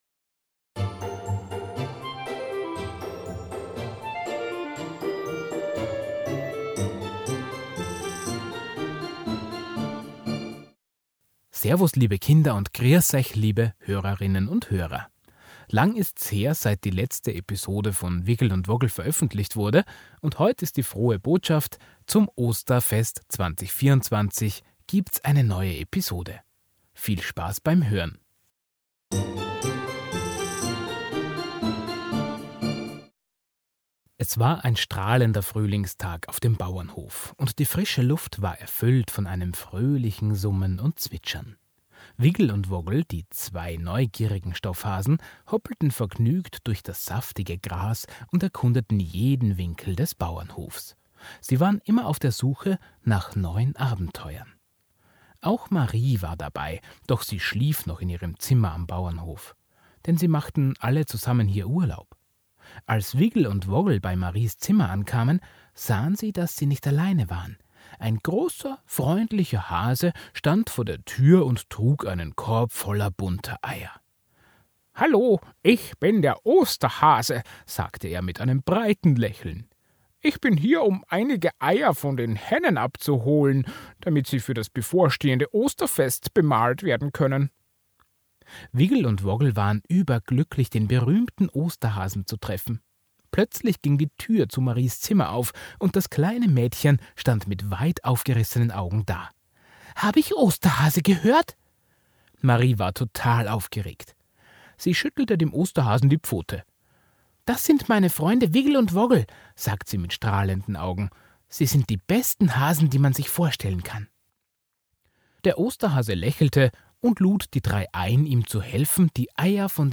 Der Wigl & Wogl Podcast – Episode 12 – Der Osterhase Wigl, Wogl und Marie sind kurz vor Ostern auf Kurzurlaub auf einem Bauernhof in Österreich. Dabei Treffen sie einen ganz besonderne Hasen. Eine Kinder-Kurzgeschichte mit österreichischem Idiom und Umgangssprache, damit den österreichischen Kindern noch ein paar heimatliche Spracheigenheiten geläufig bleiben, aber auch deutsche Kids…